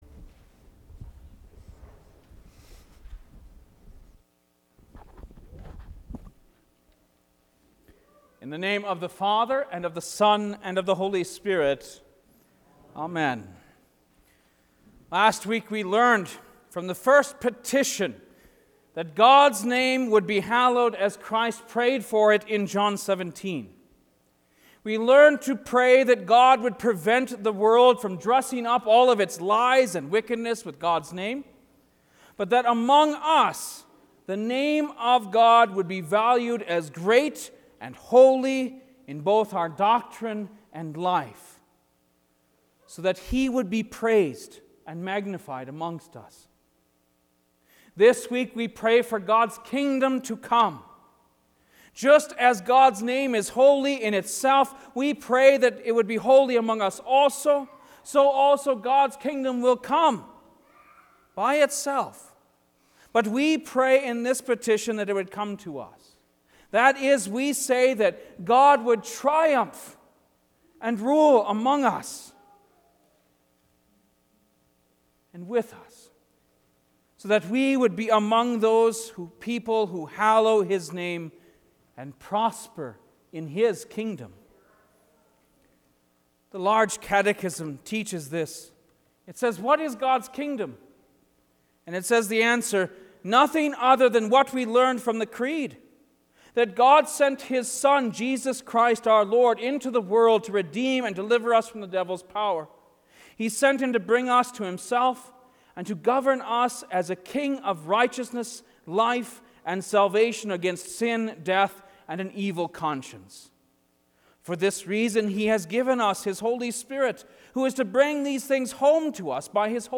Lenten Midweek Service Two